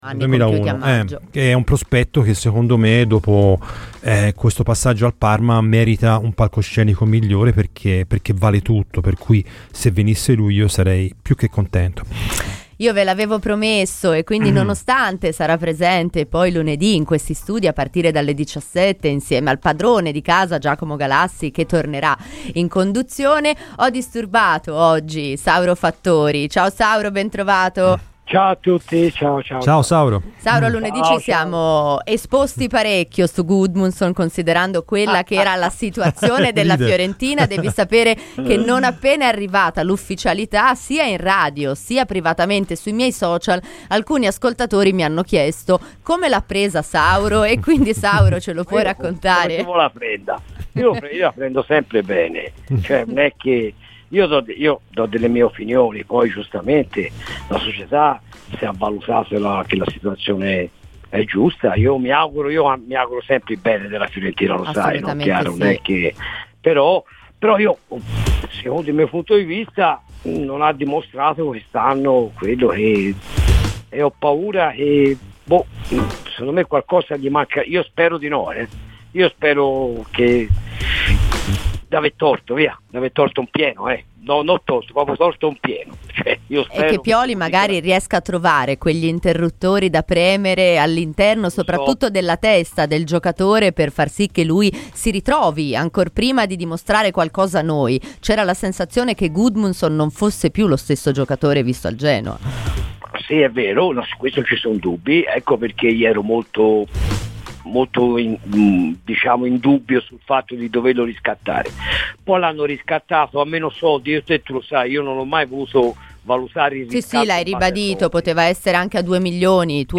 Per l'intervento completo ascolta il podcast!